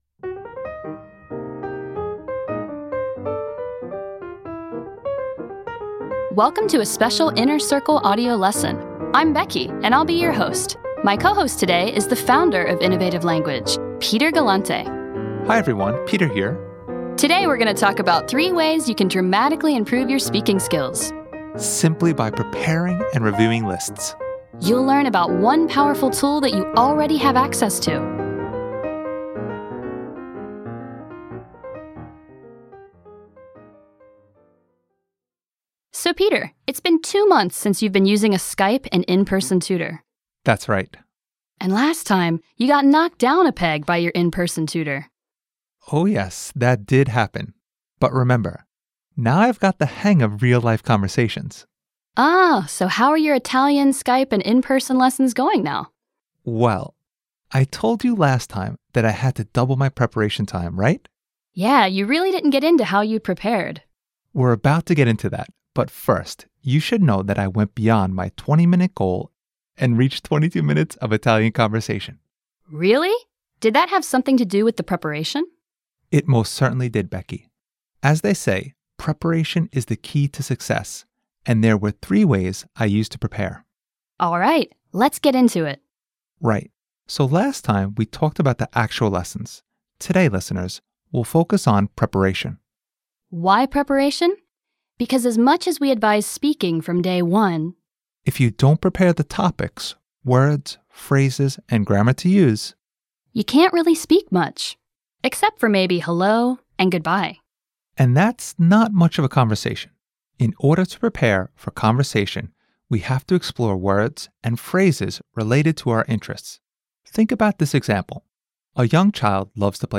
How to take advantage of the Custom Lists Web App Why you need to prepare word lists based on YOUR interests 3 Ways I used Custom Lists to reach my monthly language goal Audio Lesson Want to keep this lesson?